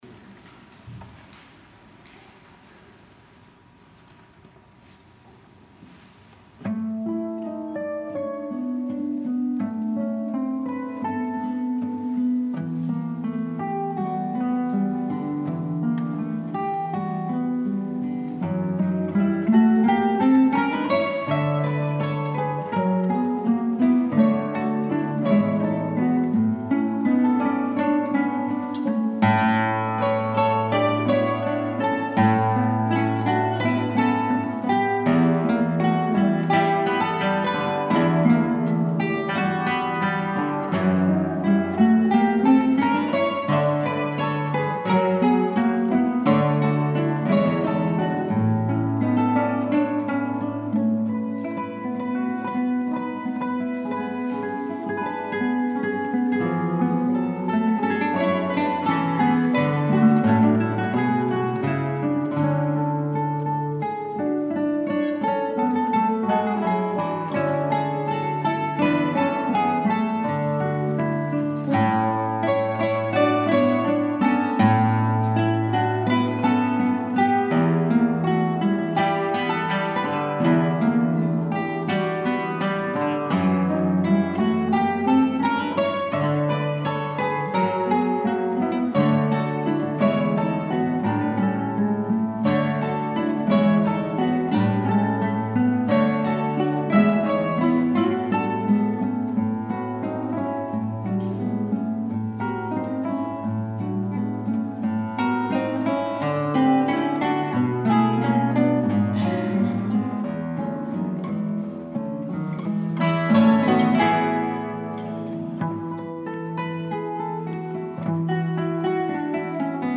(for guitar ensemble: 4 parts)